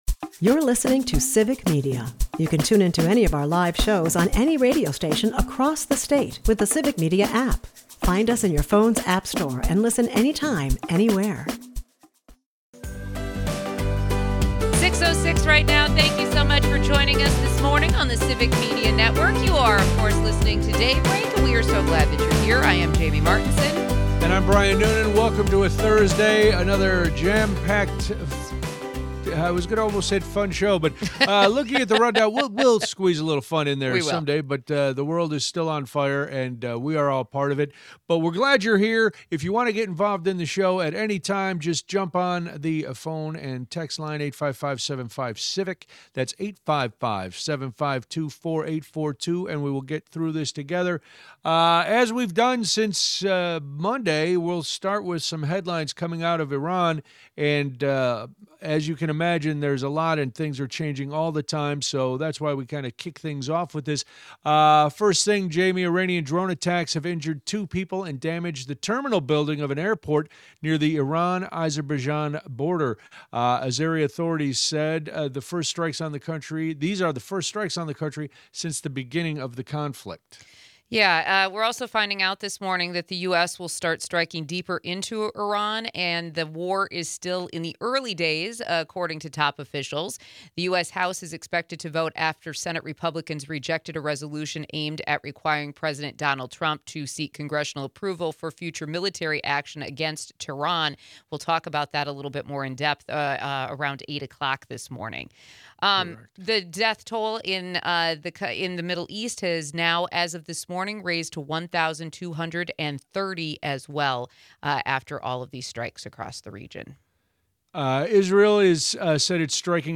We play clips of Schimel from the last year that show exactly why he shouldn’t be blindsided by that.